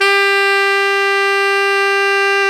SAX TENORM0V.wav